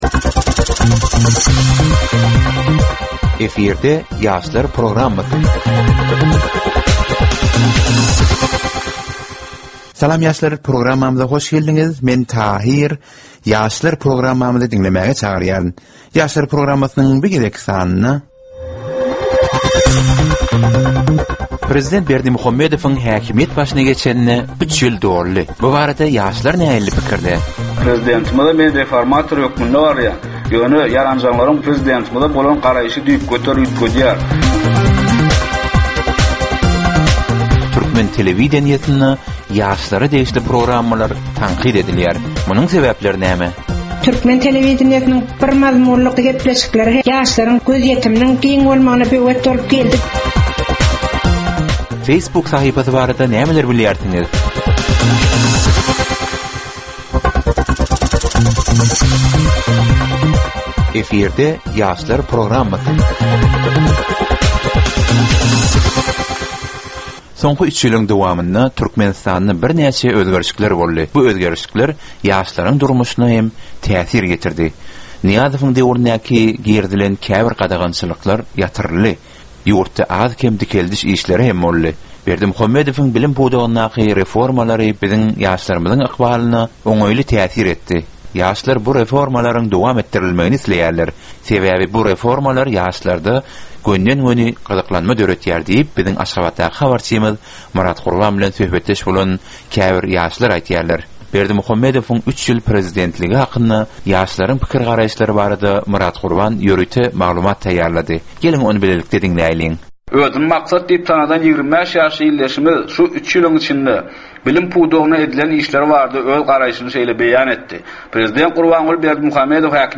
Türkmen we halkara yaşlarynyň durmuşyna degişli derwaýys meselelere we täzeliklere bagyşlanylyp taýýarlanylýan 15 minutlyk ýörite gepleşik. Bu gepleşikde ýaşlaryň durmuşyna degişli dürli täzelikler we derwaýys meseleler barada maglumatlar, synlar, bu meseleler boýunça adaty ýaşlaryň, synçylaryň we bilermenleriň pikirleri, teklipleri we diskussiýalary berilýär. Gepleşigiň dowmynda aýdym-sazlar hem eşitdirilýär.